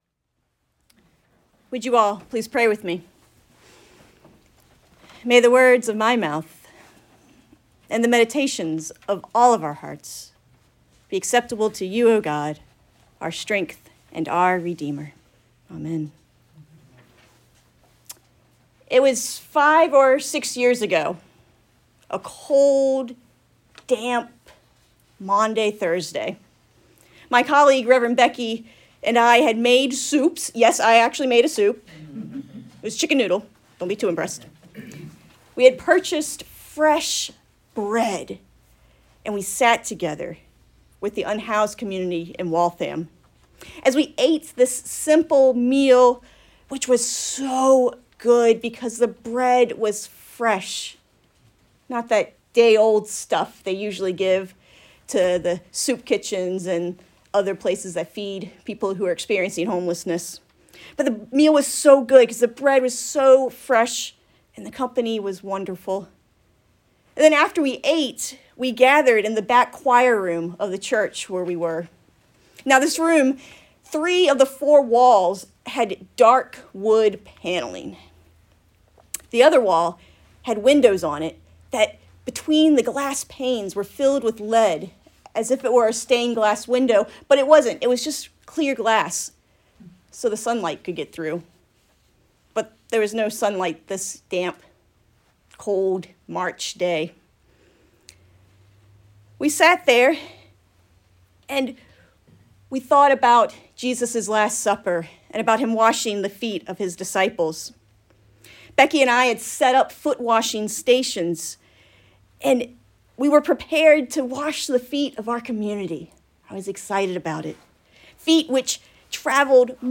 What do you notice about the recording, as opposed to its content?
Meditation from the Maundy Thursday service on April 14, 2022.